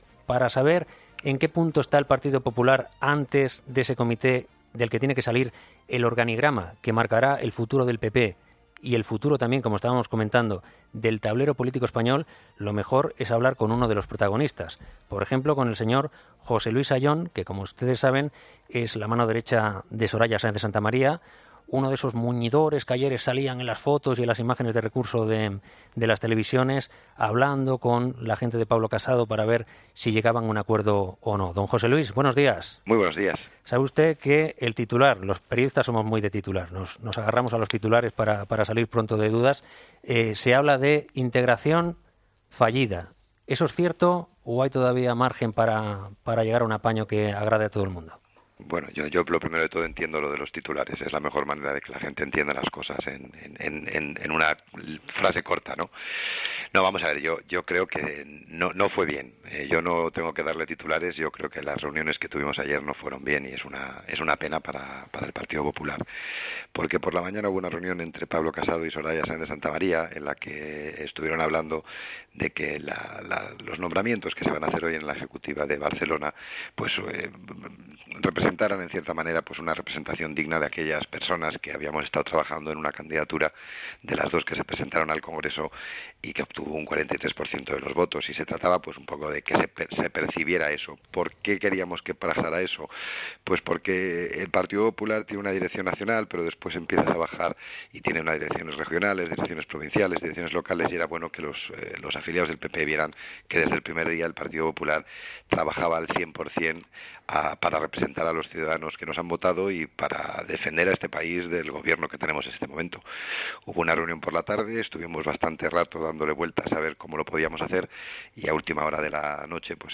Para hablar de estas negociaciones y de la situación en la que dejan al partido ha hablado en 'Herrera en COPE' Jose Luis Ayllón, uno de los "hombres fuertes" del equipo de Soraya.